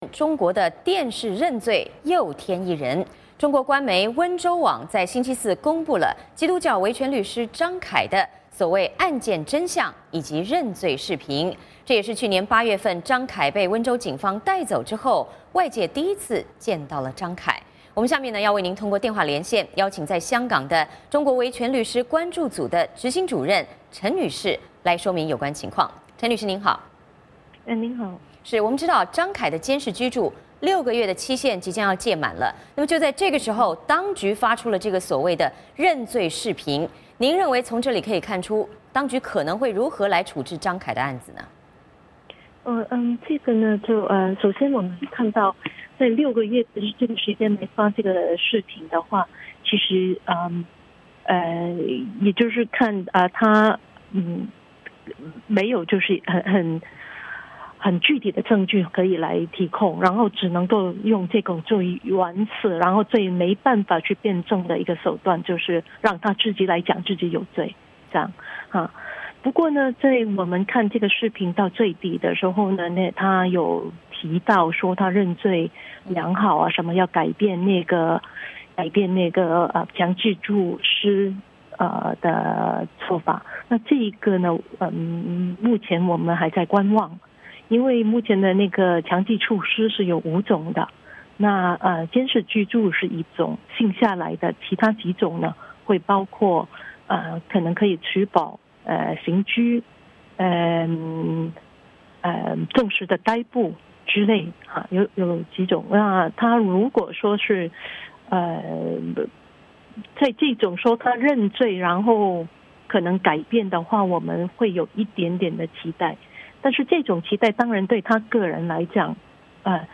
我们通过电话连线